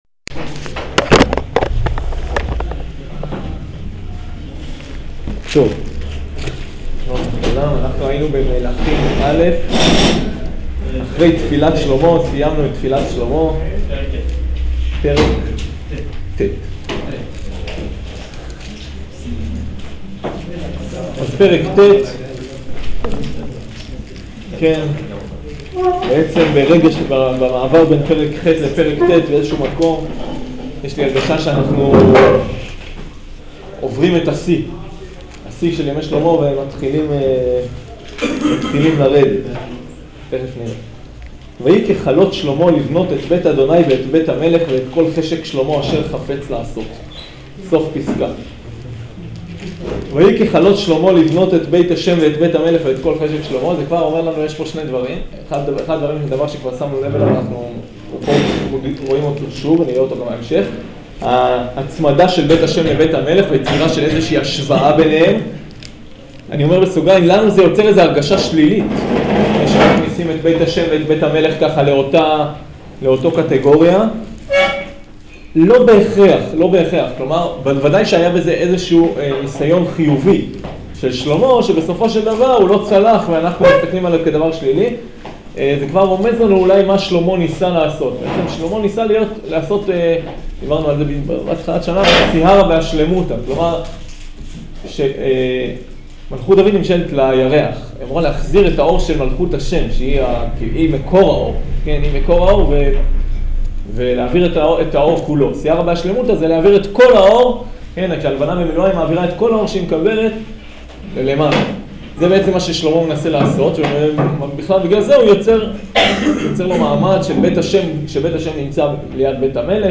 שיעור פרקים ט